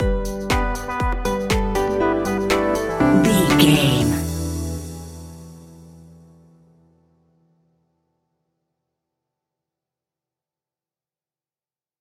Aeolian/Minor
hopeful
synthesiser
drum machine
electric piano
acoustic guitar
electronic music
synth bass